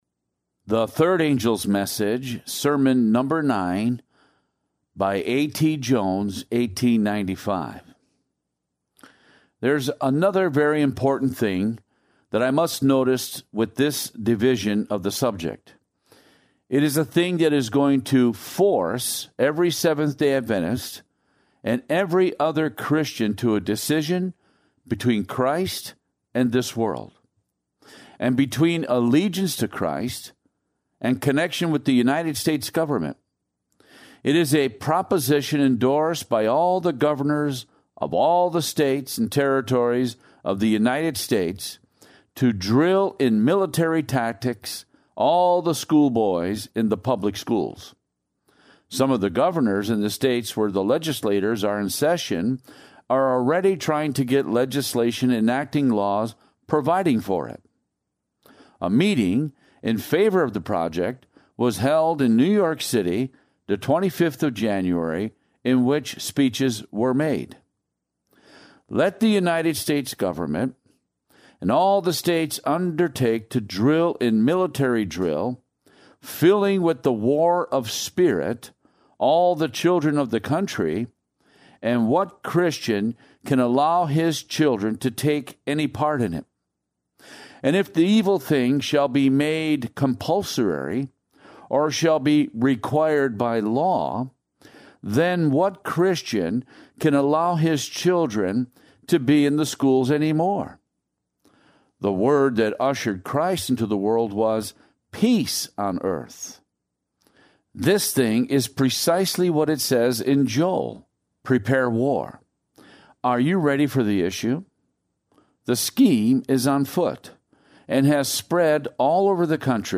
A Series of 26 Sermons Given at the General Conference of 1895 and Recorded in The General Conference Bulletin the Same Year